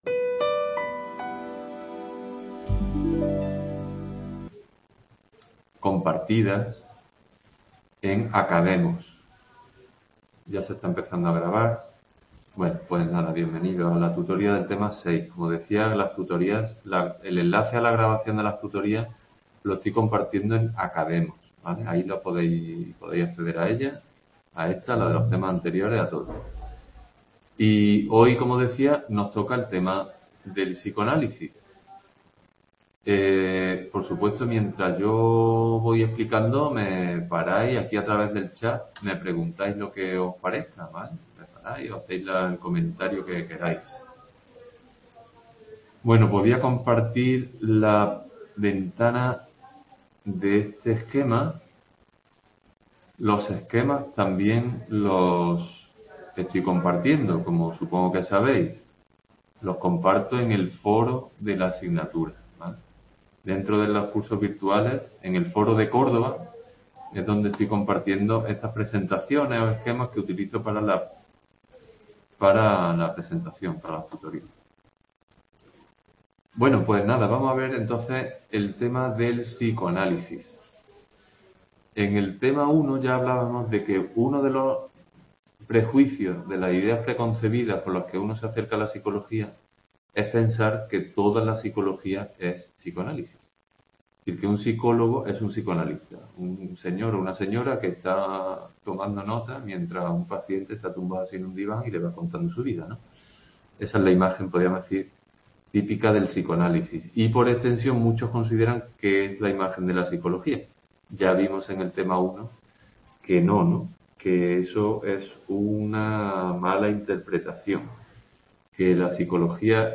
Tutoría Psicología Tema 6. Primera parte | Repositorio Digital